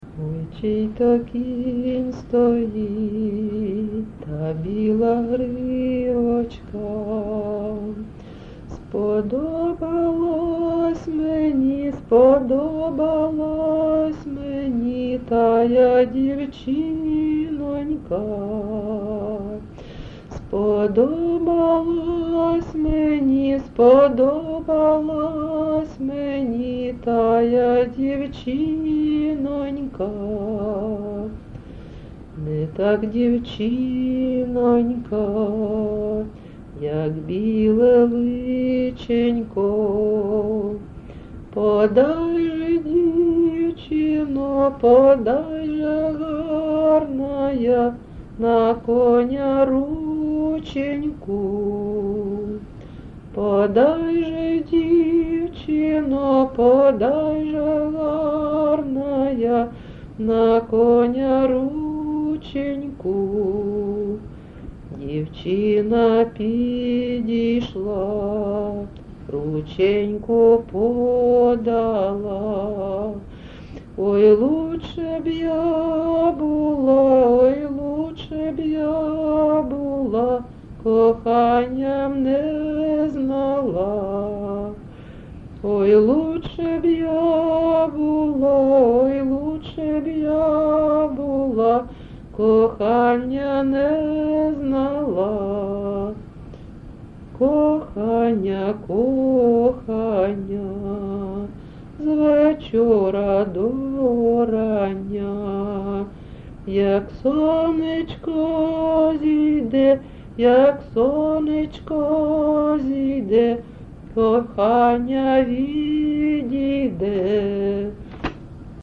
ЖанрПісні з особистого та родинного життя
Місце записус-ще Олексієво-Дружківка, Краматорський район, Донецька обл., Україна, Слобожанщина